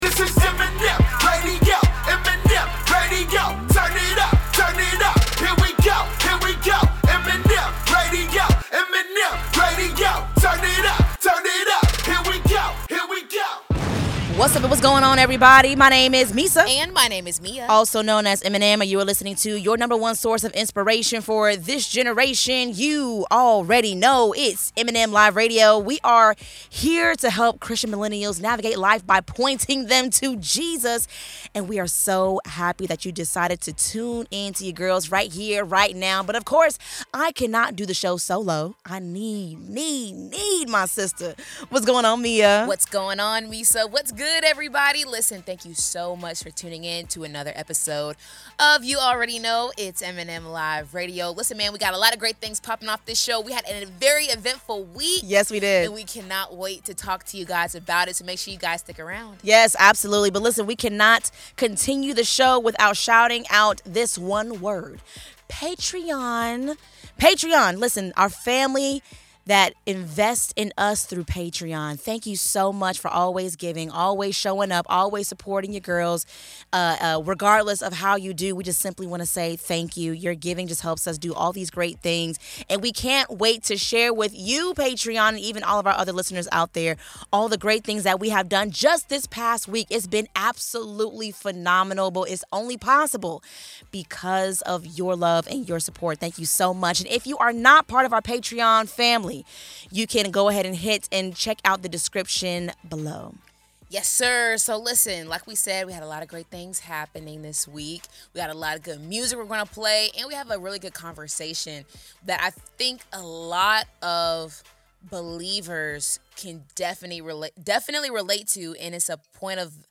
Through inspiring music, powerful stories, and thought-provoking interviews, this is the show where faith meets culture—all to point you back to Jesus.